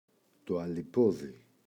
αλιπόδι, το [aliꞋpoði]